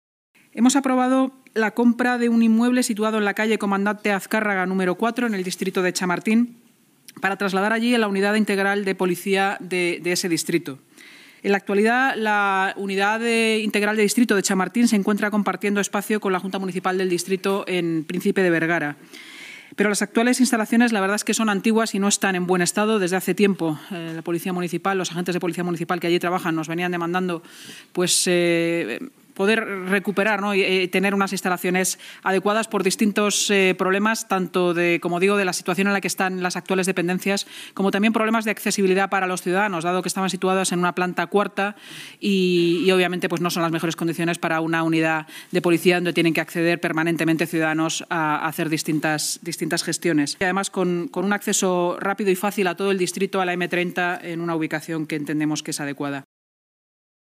Nueva ventana:La delegada de Seguridad y Emergencias y portavoz municipal, Inmaculada Sanz, explica durante la rueda de prensa los detalles de la nueva sede en Comandante Azcárraga, 4